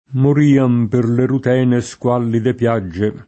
mor&am per le rut$ne SkU#llide pL#JJe] (Leopardi); e con acc. scr.: le nevi De’ rutèni deserti [